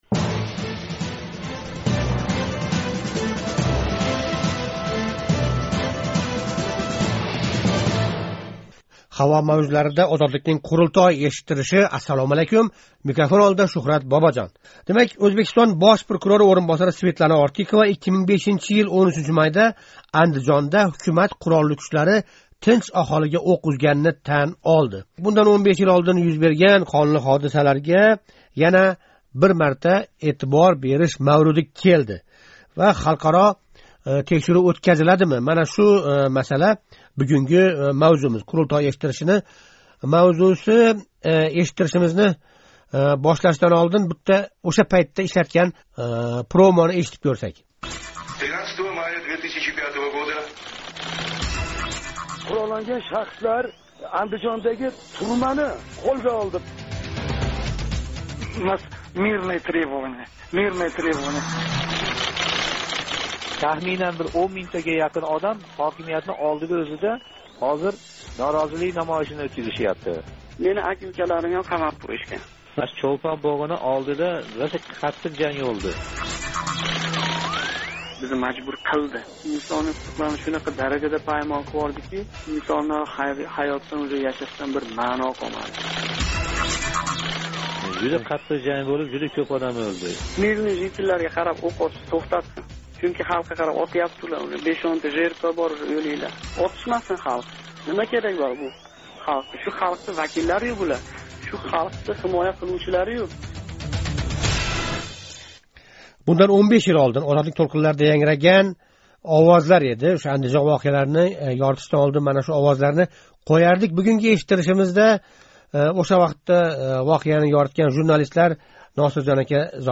Ўзбекистон Бош прокурори ўринбосари Светлана Ортиқова 2005 йил 13 майда Андижонда ҳукумат қуролли кучлари тинч аҳолига ўқ узганини тан олди. Озодликнинг “Қурултой” эшиттириши қатнашчилари навбатдаги қадам сифатида “мустақил текширув” ўтказиш лозимлигини урғулашди.